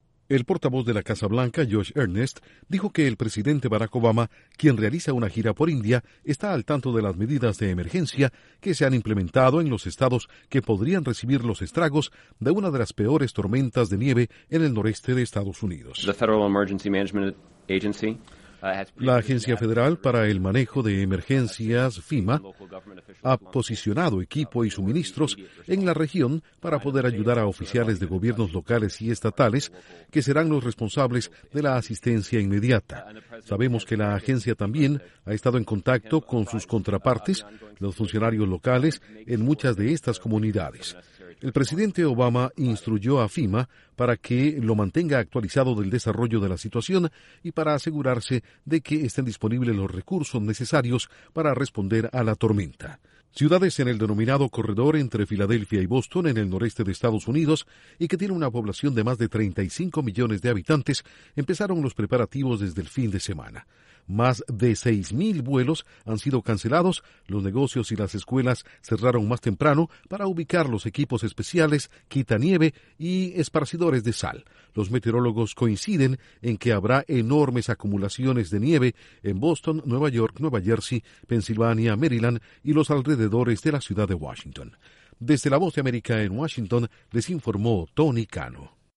Casa Blanca: agencias de emergencia están preparadas para enfrentar los efectos de una potente tormenta de nieve en varios estados del noreste de EEUU. Informa desde los estudios de la Voz de América en Washington